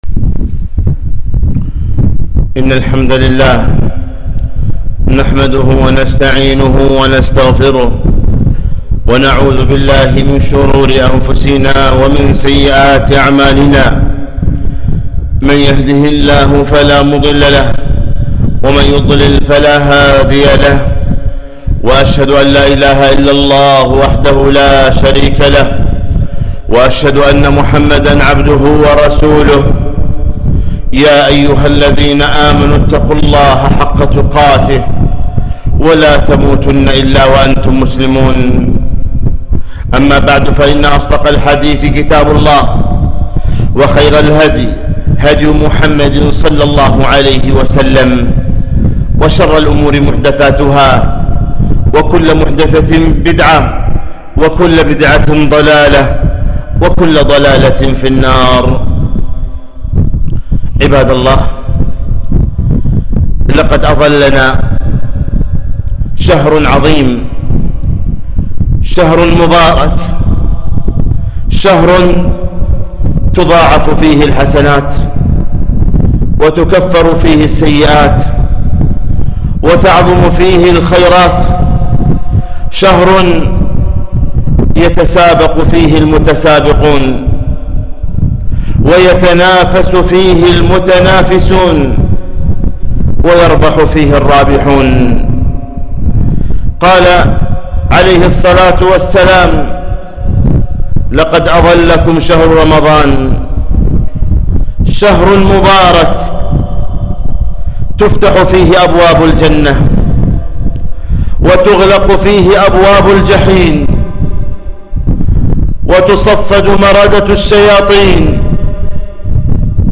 خطبة - استقبال شهر رمضان